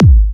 VEC3 Bassdrums Trance 69.wav